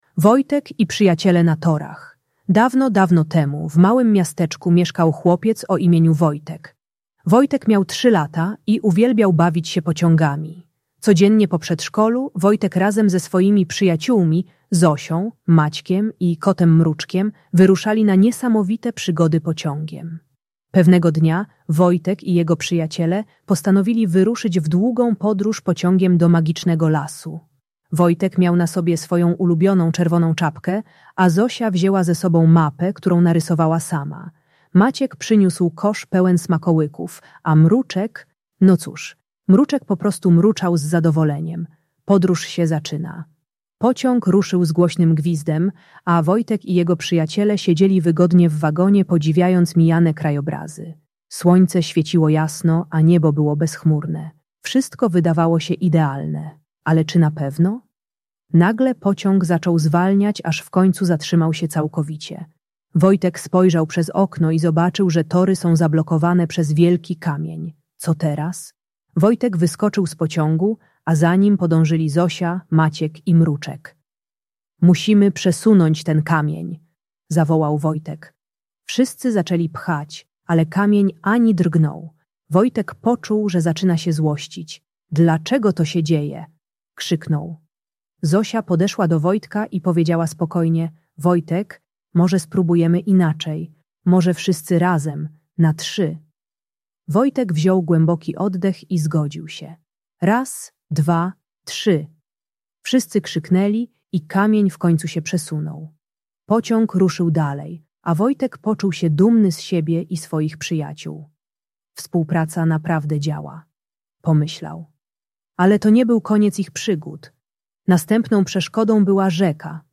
Wojtek i Przyjaciele na Torach - Bunt i wybuchy złości | Audiobajka